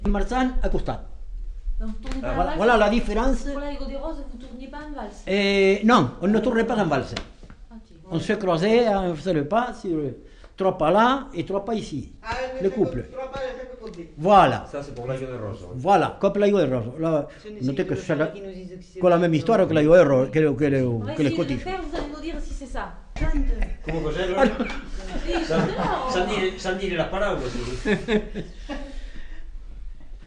Aire culturelle : Agenais
Genre : témoignage thématique
Ecouter-voir : archives sonores en ligne